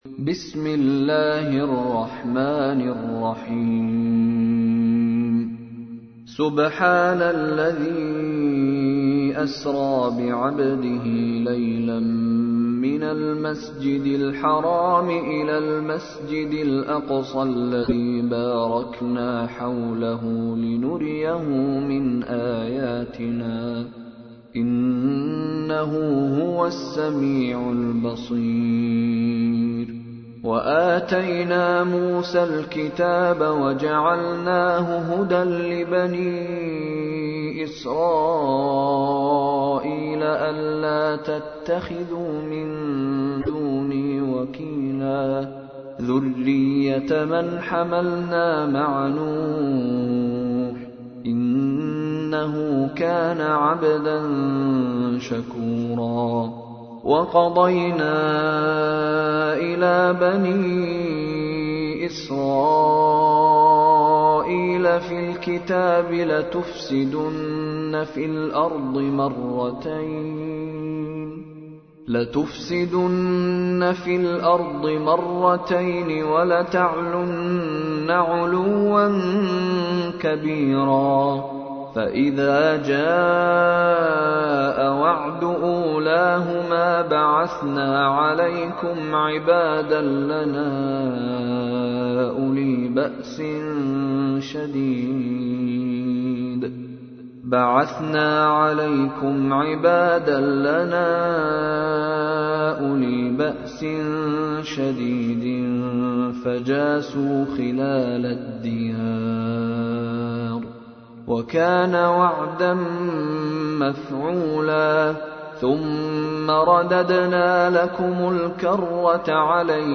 تحميل : 17. سورة الإسراء / القارئ مشاري راشد العفاسي / القرآن الكريم / موقع يا حسين